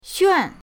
xuan4.mp3